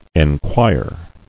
[en·quire]